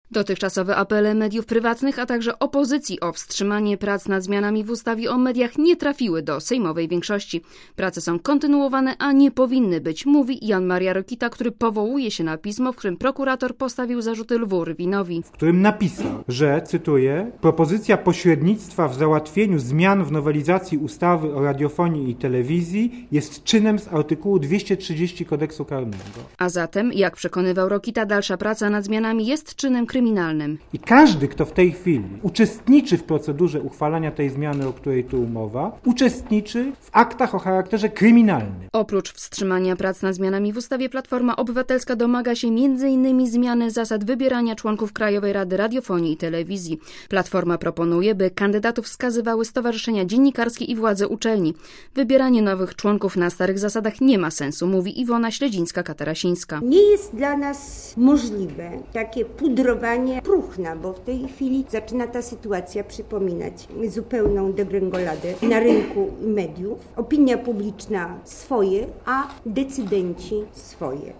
Relacja reportera Radia Zet (556Kb)